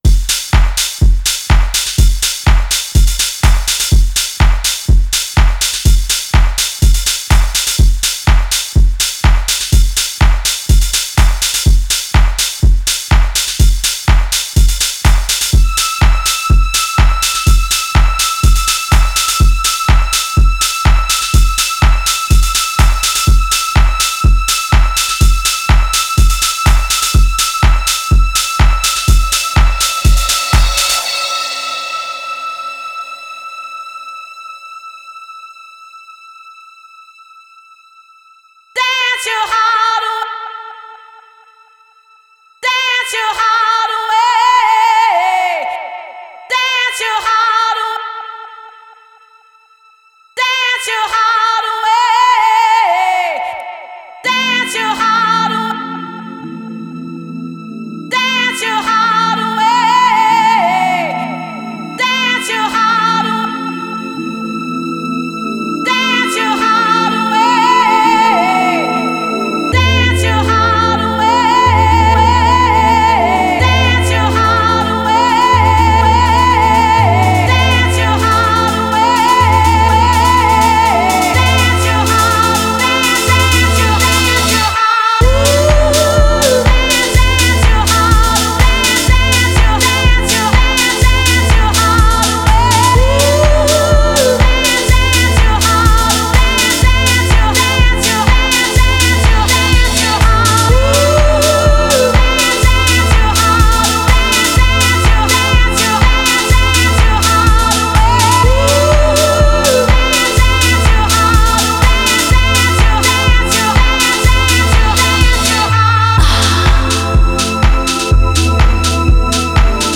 Genre: Dance, Disco, Nu-Disco, Funk